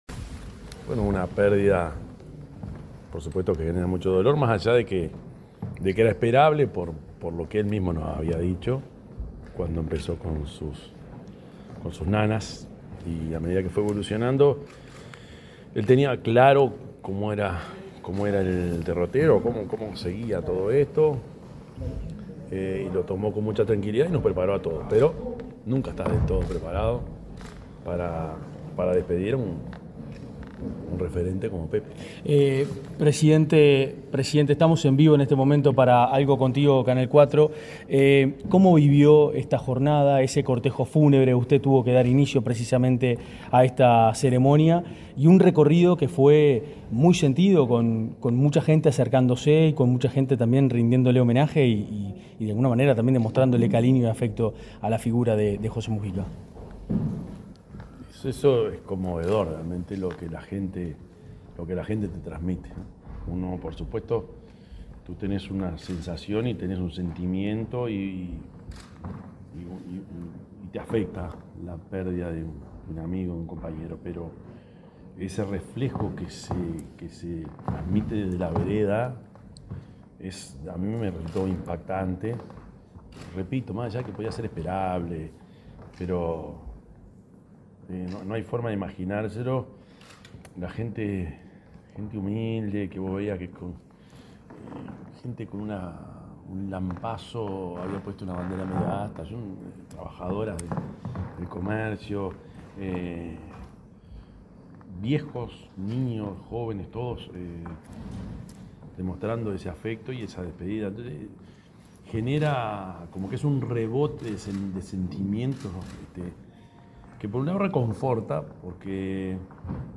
El presidente de la República, profesor Yamandú Orsi, dialogó con la prensa en el Palacio Legislativo, acerca de la figura y el legado del